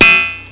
snd_128_Metal.wav